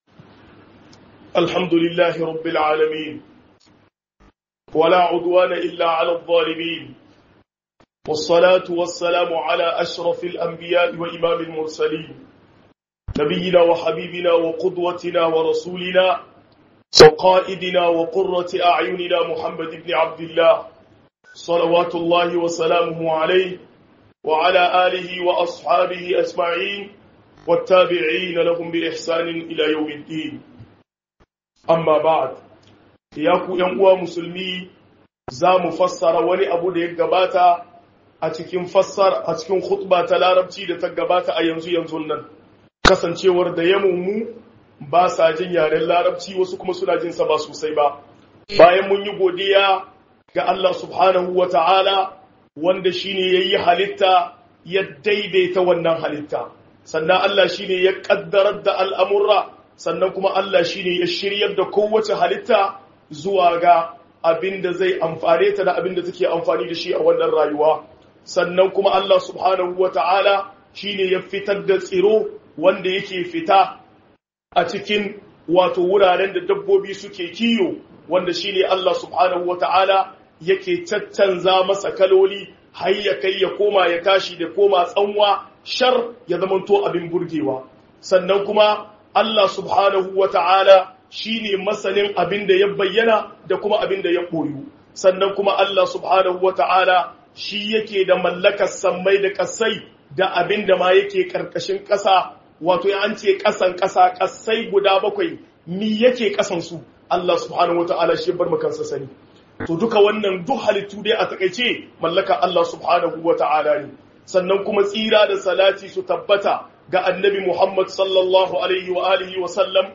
002-Tsoratarwa game da duniya - KHUƊUBAR JUMA’A (HAUSA)